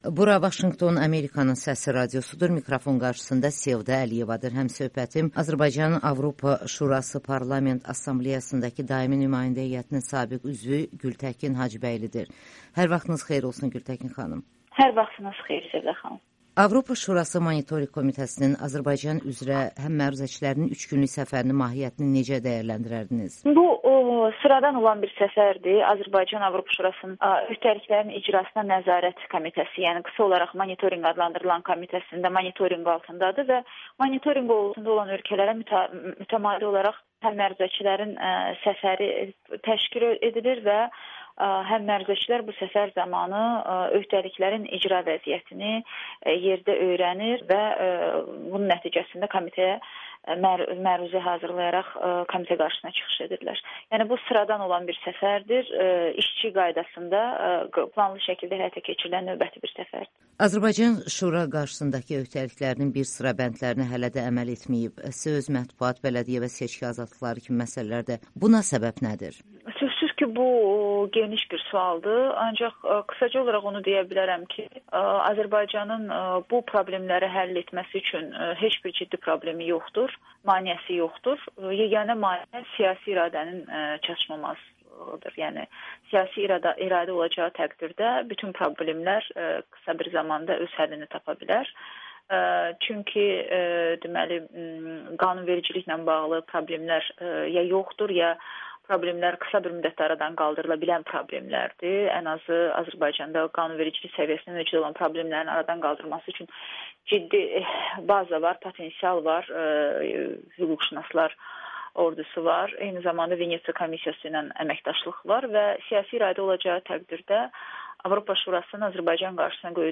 Gültəkin Hacıbəyli: Biz siyasi məhbuslar üzrə dünya çempionuyuq [Audio-Müsahibə]